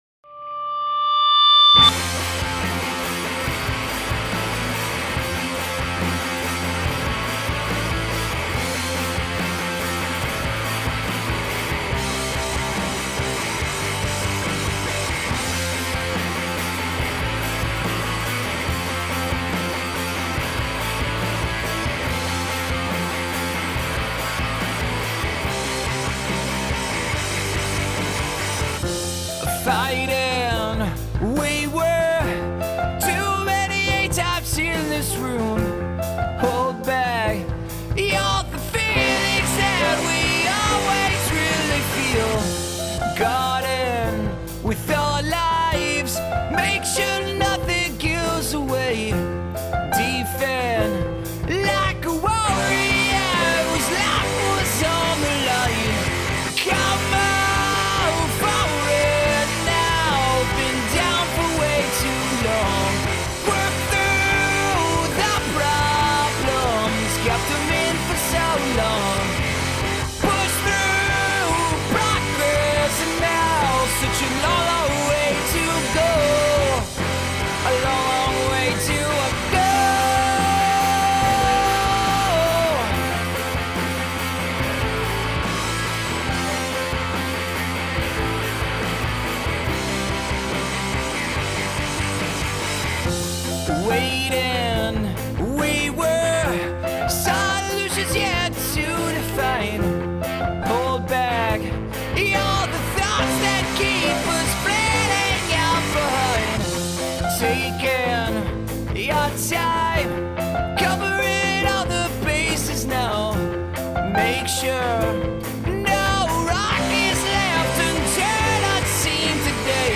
This was mixed on cans in Reaper using various free plugs.